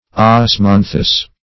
Osmanthus - definition of Osmanthus - synonyms, pronunciation, spelling from Free Dictionary
osmanthus.mp3